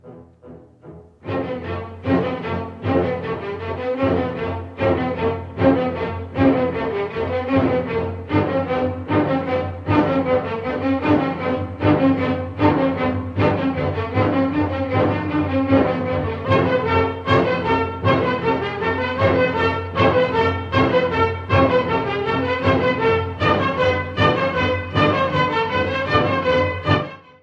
enormous dynamism
excerpts are from original film soundtrack.